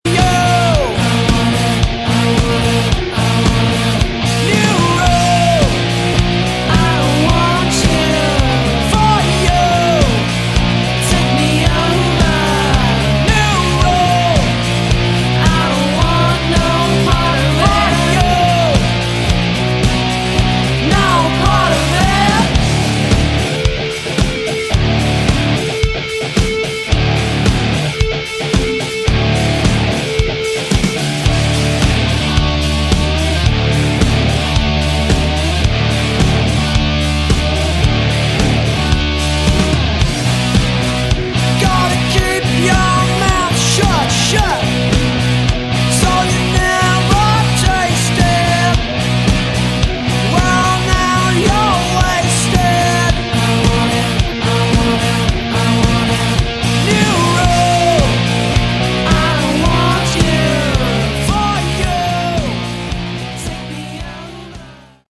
Category: Sleaze Glam/Punk
vocals, guitar
bass, vocals
drums